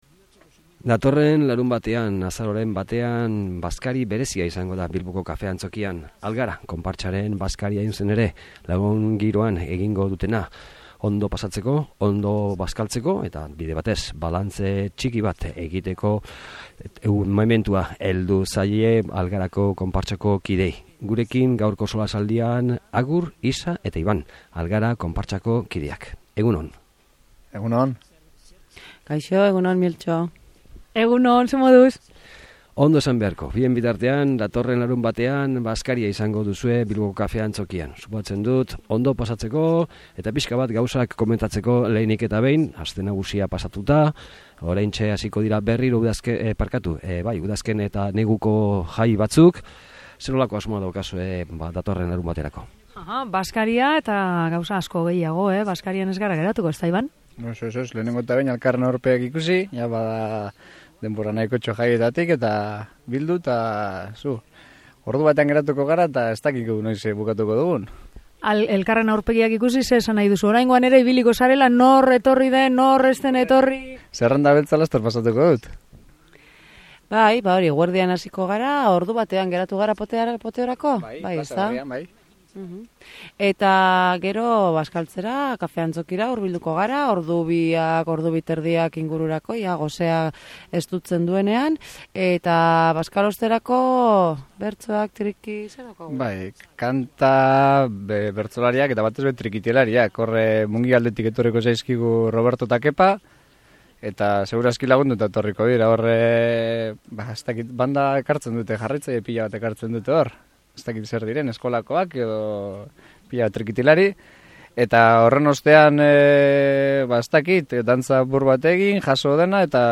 SOLASALDIA: Algara konpartsa | Bilbo Hiria irratia